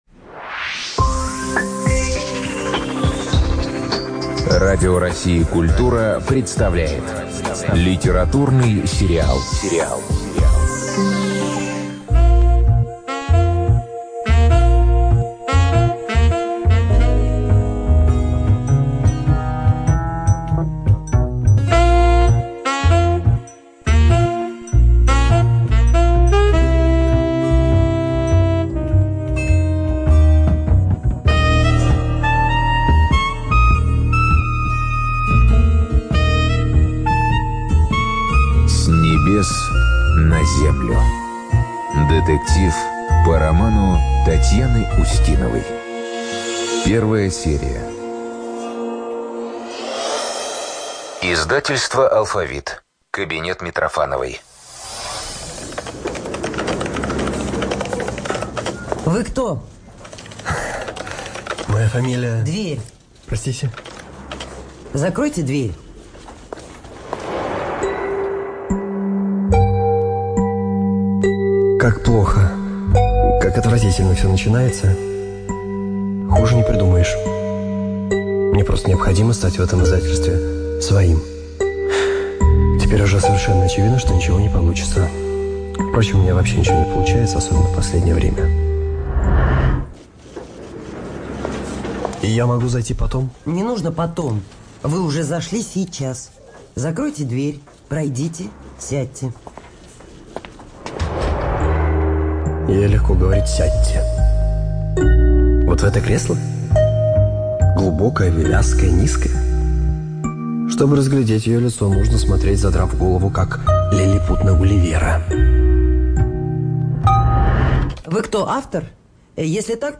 ЖанрРадиоспектакли